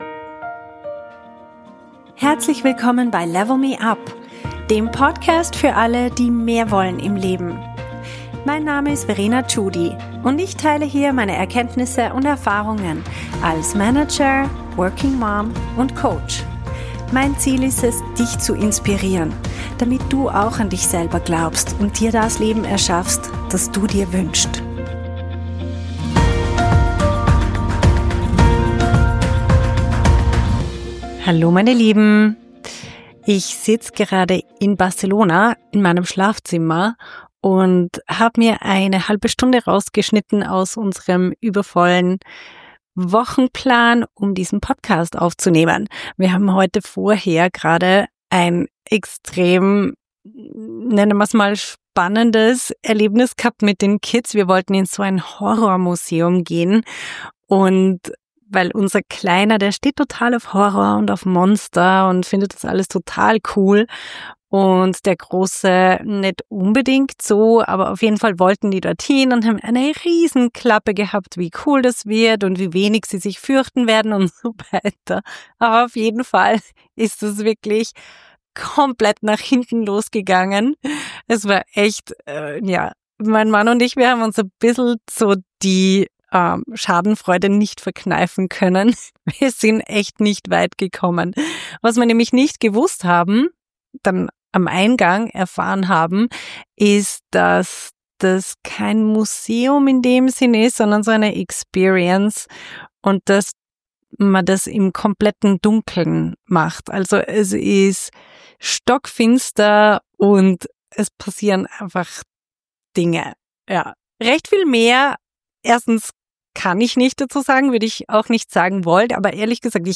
In dieser Folge erwähnter Link: Female Leaders Academy Ich nehme dich heute mit nach Barcelona in mein Schlafzimmer mitten im Familienchaos.